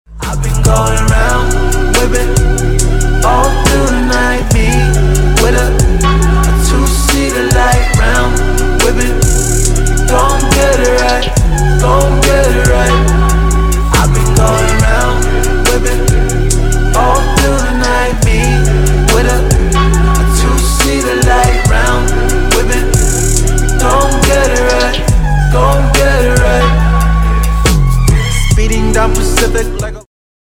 • Качество: 320, Stereo
мужской вокал
Хип-хоп
RnB